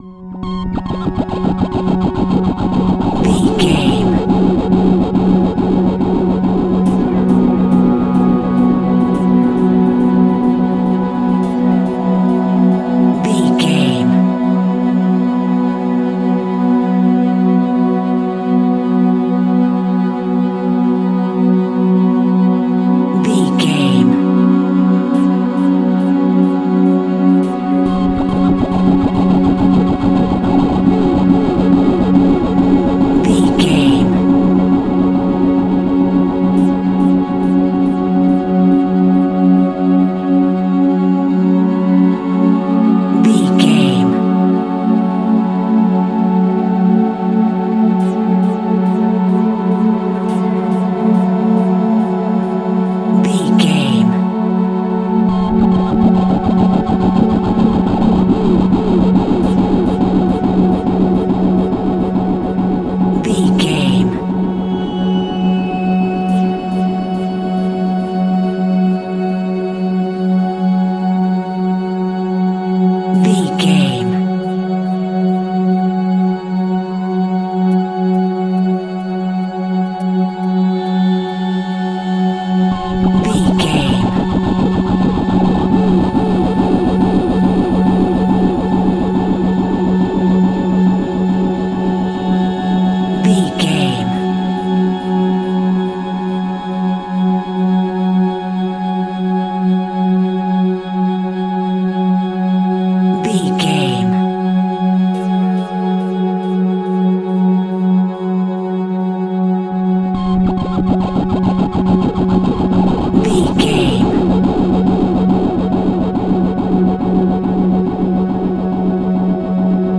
Scary Crickets.
Thriller
Aeolian/Minor
tension
ominous
eerie
synthesiser
ambience
pads